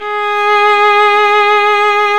Index of /90_sSampleCDs/Roland - String Master Series/STR_Violin 1-3vb/STR_Vln2 _ marc
STR  VL G#5.wav